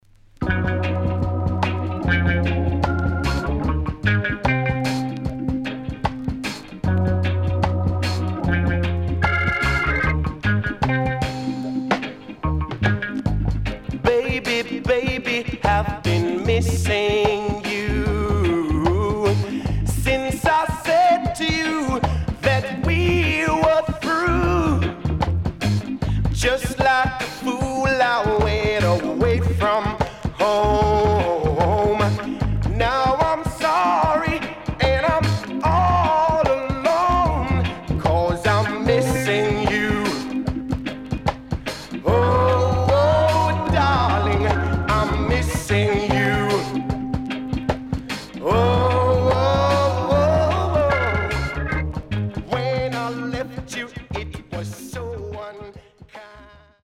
HOME > Back Order [VINTAGE LP]  >  EARLY REGGAE
SIDE A:所々チリノイズ、プチノイズ入ります。